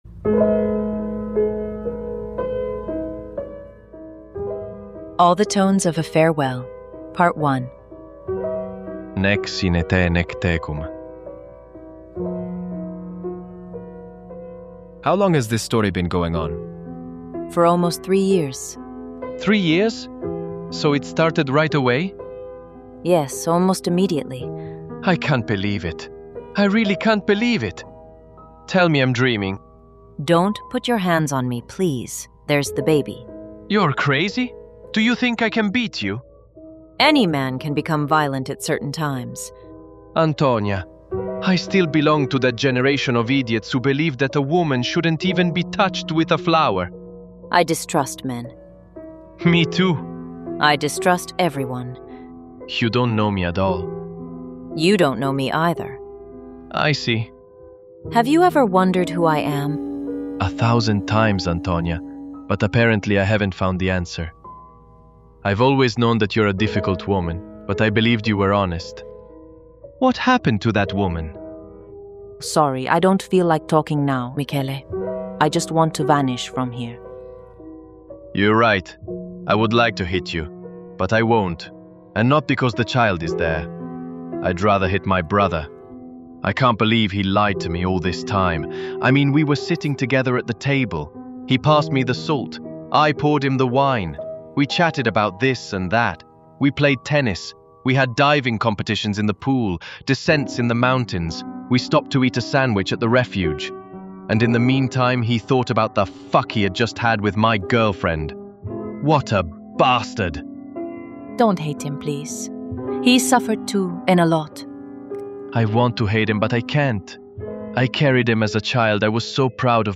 4.1. All the tones of a farewell - Part I (Antonia is leaving) - Emmanuel - The broken diary (Podcast Novel)